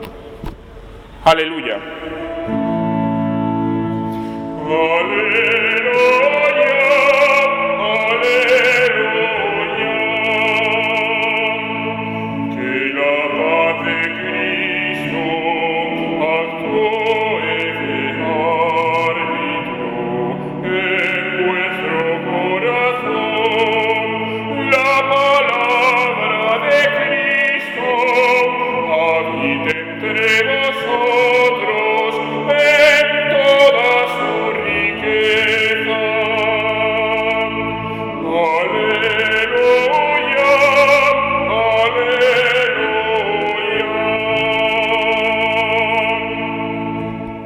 domingo14caleluya.mp3